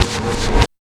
81 NOISE  -L.wav